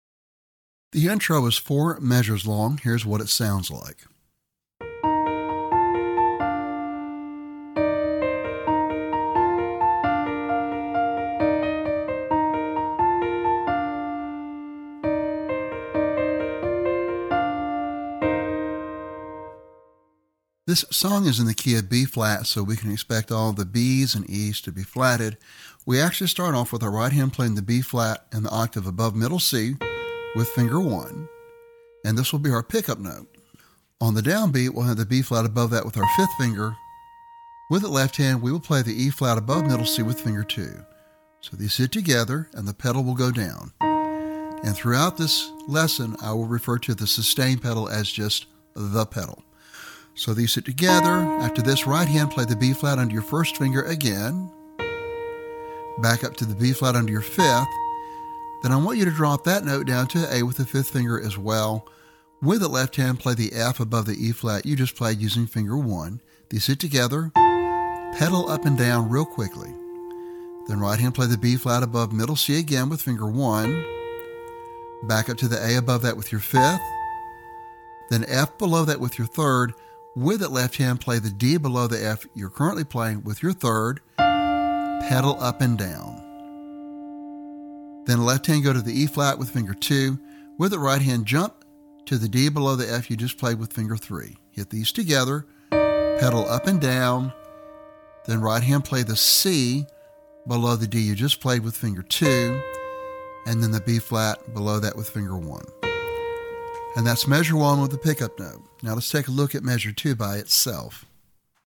great upbeat piano solo
This song is rated at an intermediate level.